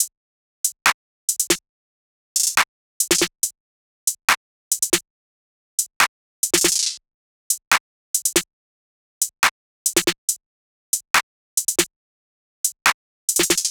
SOUTHSIDE_beat_loop_butter_top_02_140.wav